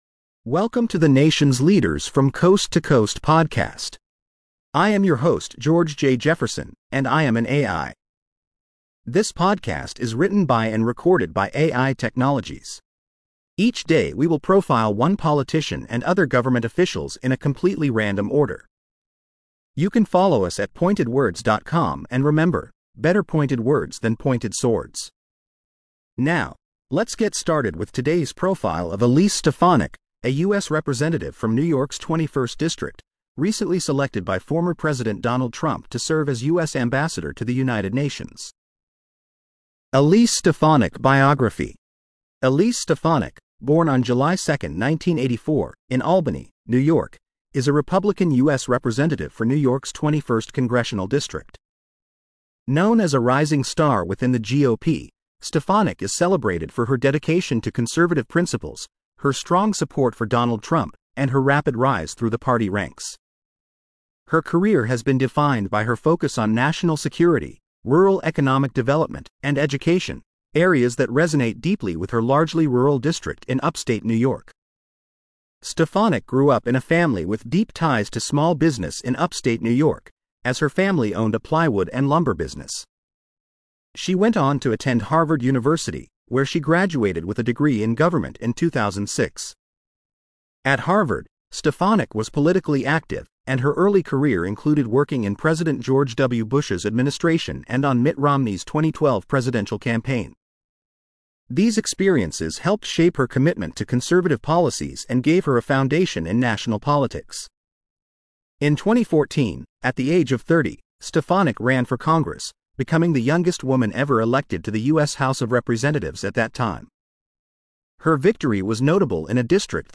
Ai Created.